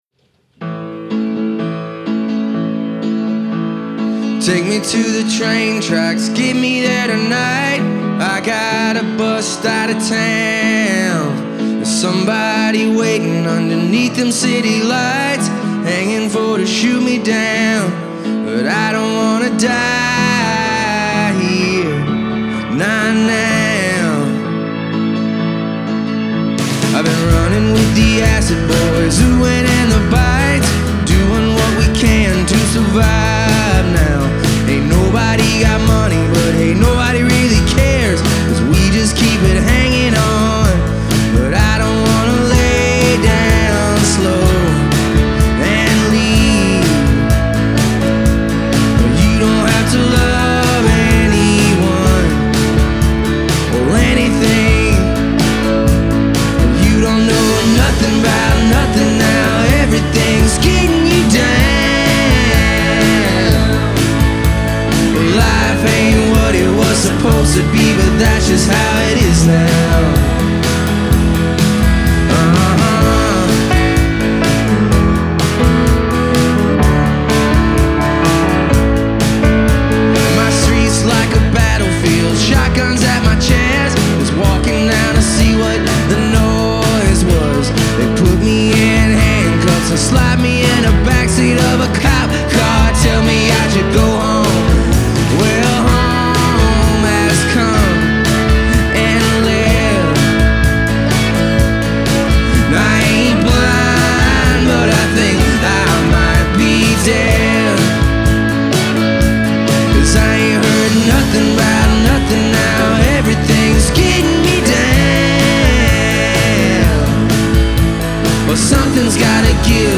Or the rough-hewn voices either, for that matter.